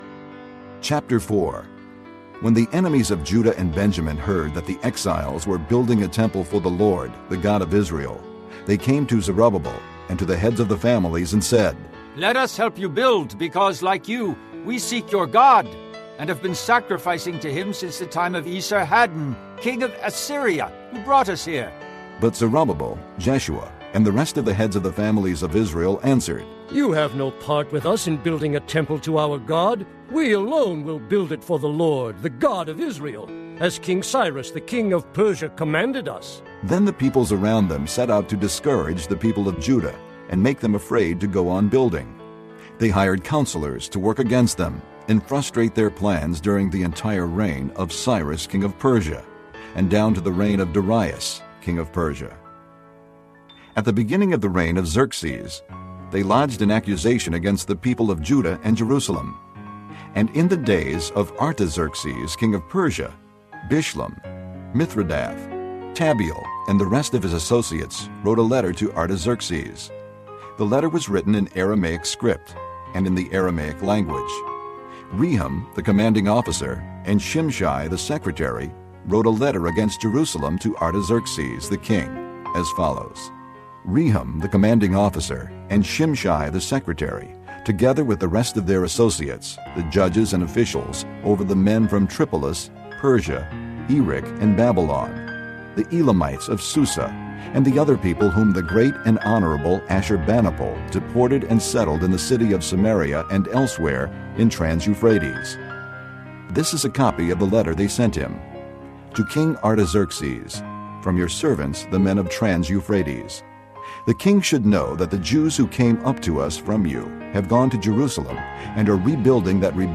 圣经导读&经文朗读 – 07月02日（音频+文字+新歌）